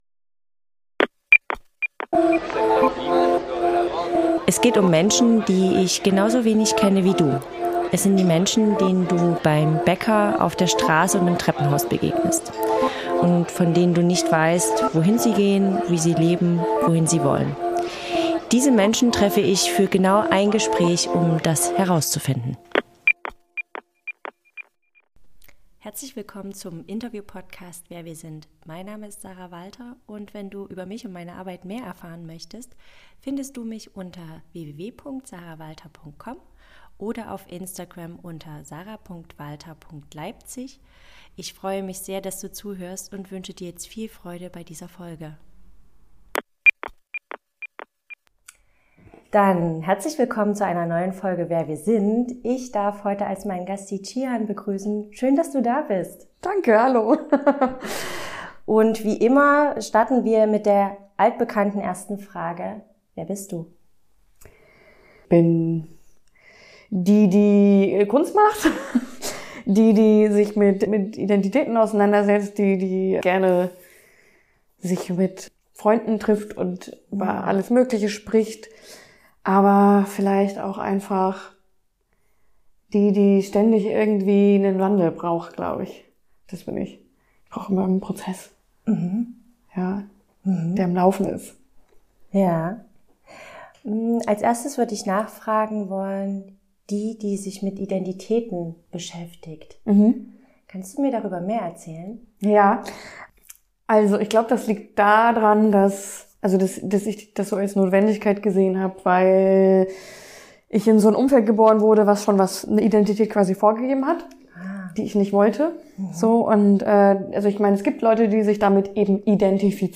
- ein Gespräch. ohne Skript. ohne Labels.